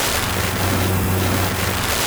lightning.ogg